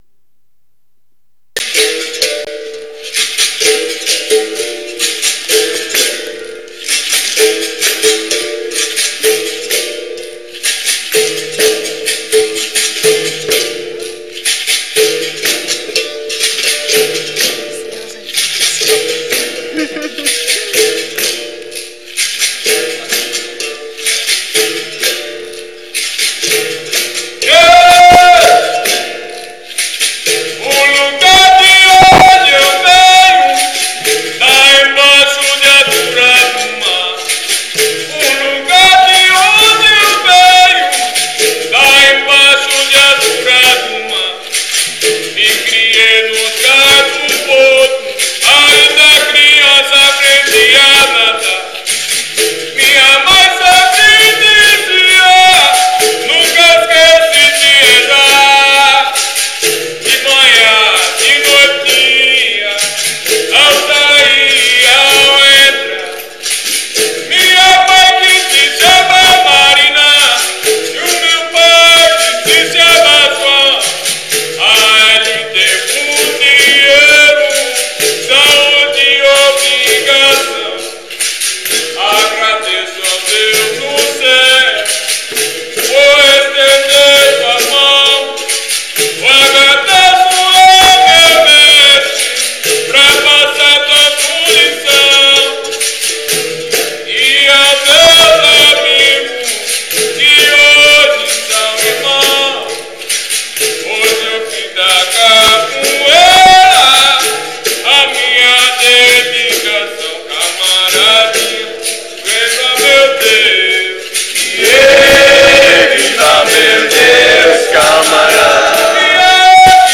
The text of the first ladainha that was sung at the Capoeira Picknick Vol. 2 in Leuven 2010: